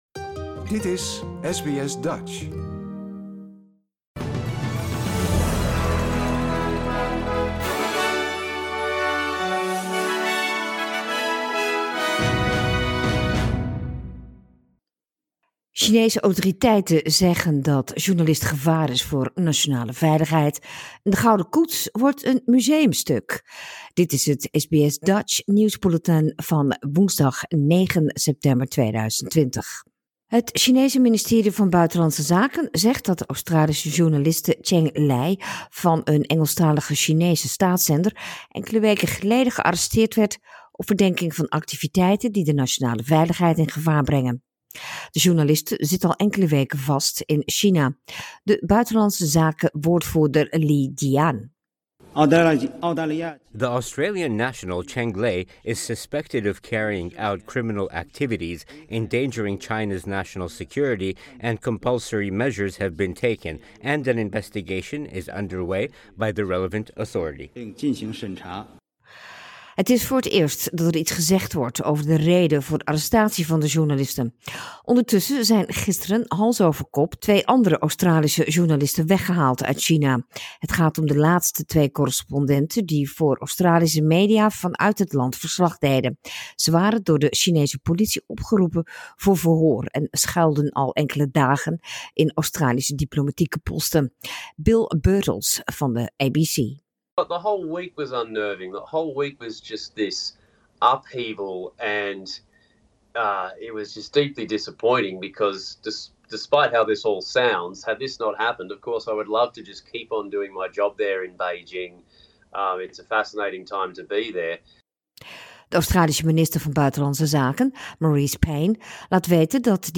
Nederlands/Australisch SBS Dutch nieuwsbulletin woensdag 9 september 2020